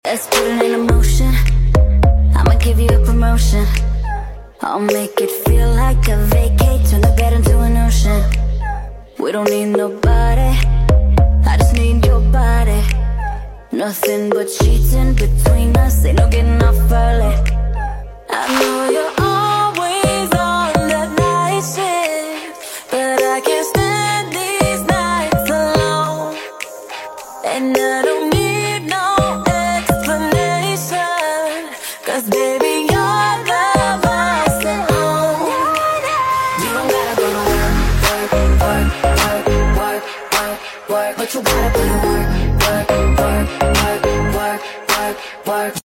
deira,abra river Dubai sound effects free download